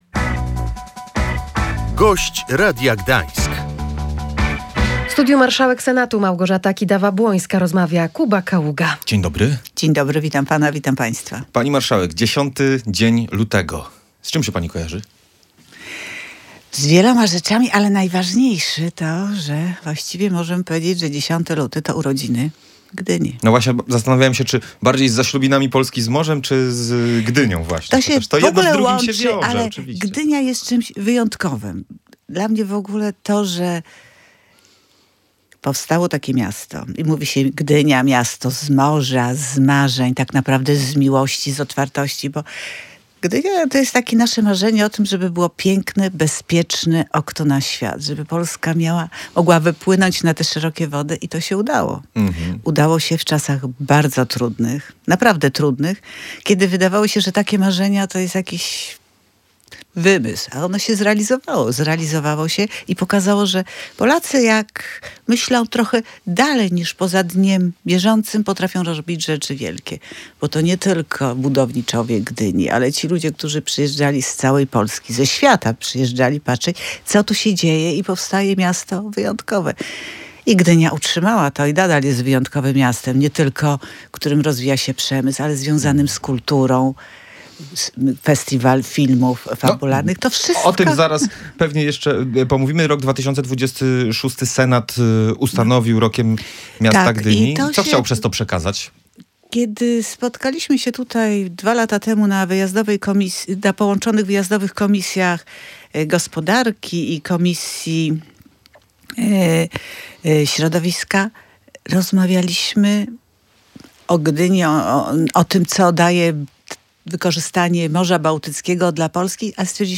Gdynia jest symbolem spełnienia marzeń Polaków – mówiła na antenie Radia Gdańsk marszałek Senatu Małgorzata Kidawa-Błońska. Podkreślała, że to wyjątkowe miasto, które przez ostatnie sto lat jest bezpiecznym oknem na świat.